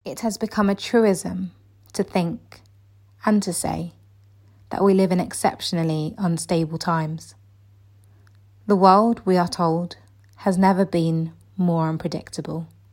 영국 여자 성우